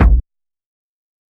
DrKick87.WAV